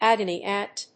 アクセントágony àunt